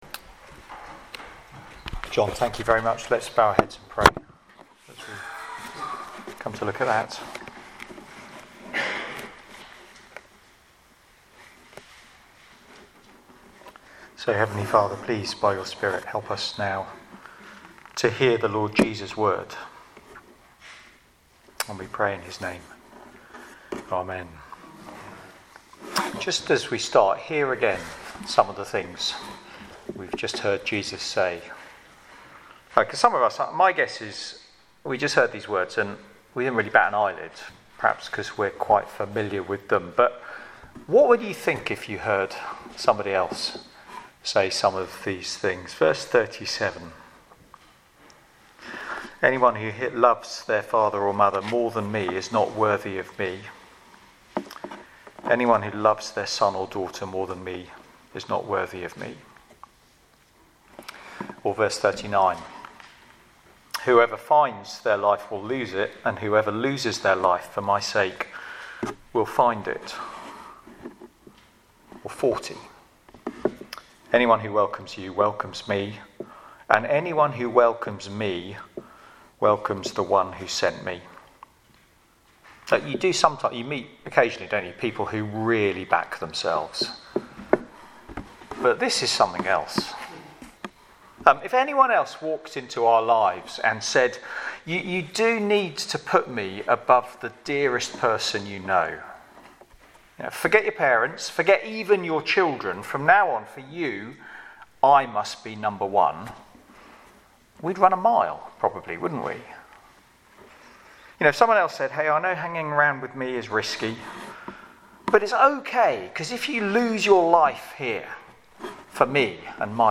Media for Sunday Evening on Sun 22nd Sep 2024 18:00
Theme: Sermon